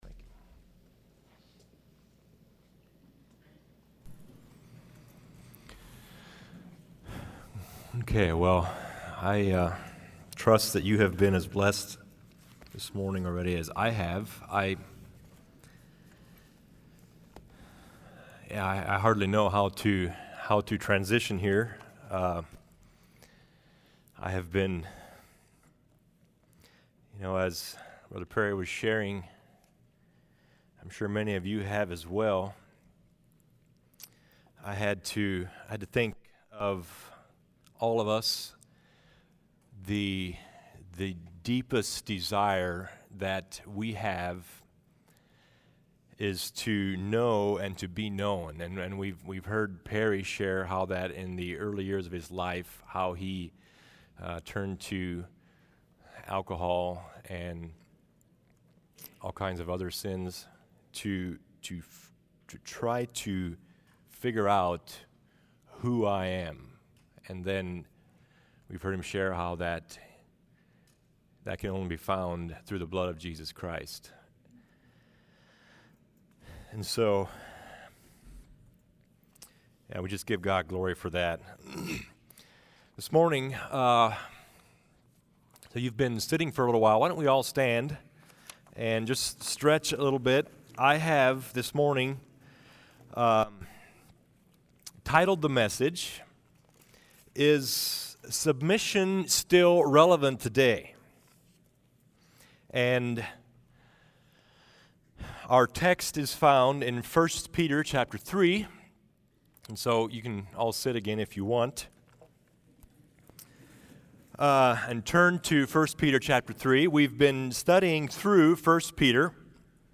Home Sermons Is Submission Still Relevant Today?